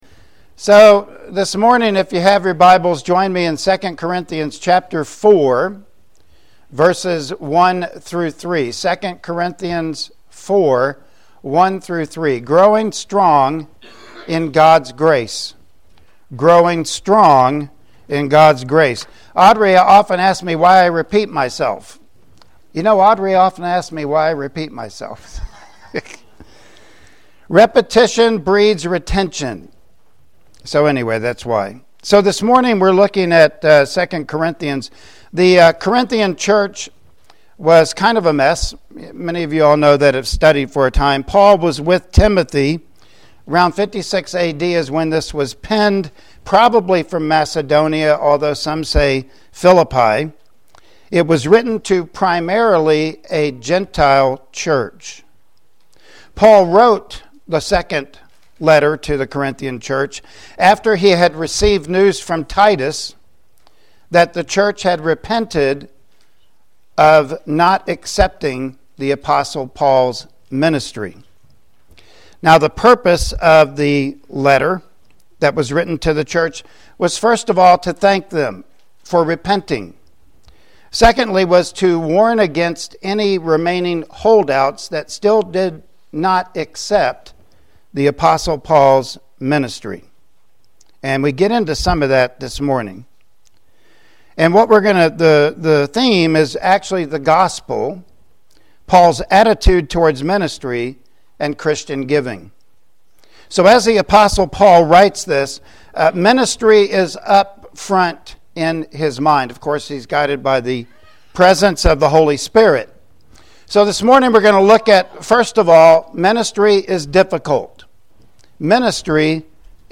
2 Corinthians Passage: 2 Corinthians 4:1-3 Service Type: Sunday Morning Worship Service Topics